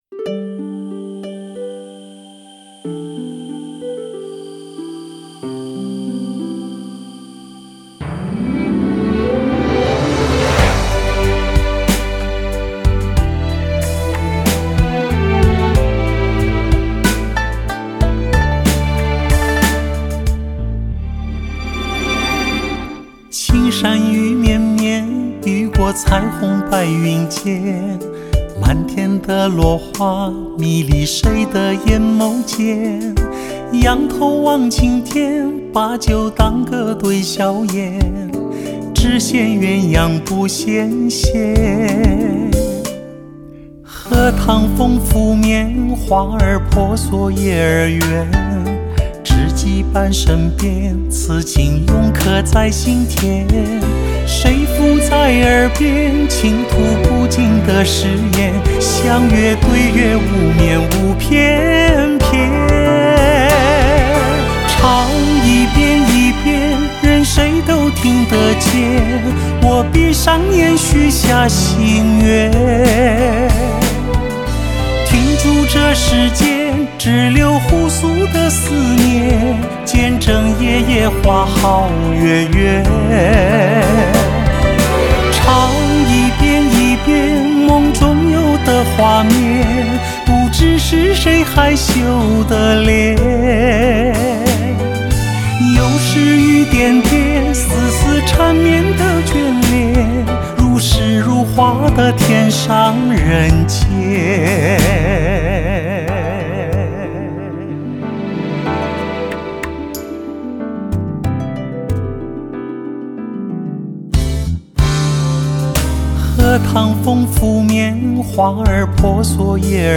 中國風味R&B
兼具流行與古典的中國式R&B曲風中
著輕柔如羽毛般的嗓音，在中國交響樂團弦樂的襯托之下，讓人融化在歌曲的涵意